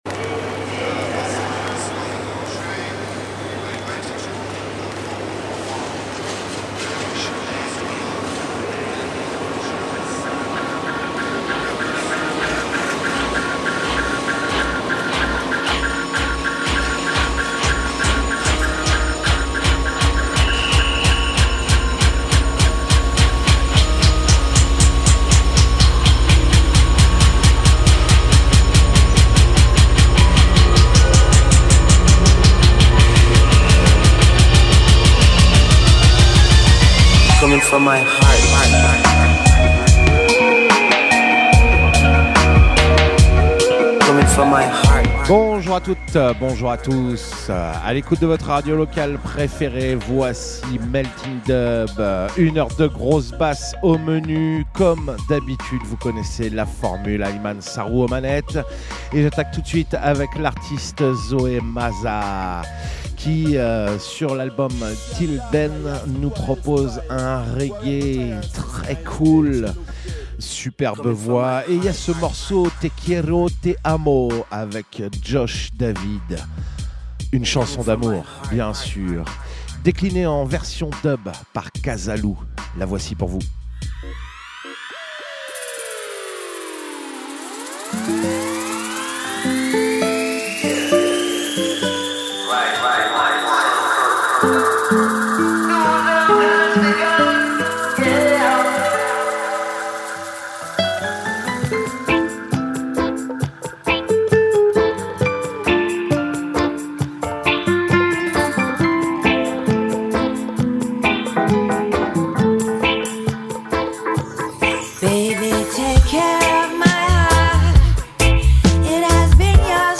bass music , dub , musique , musique electronique , reggae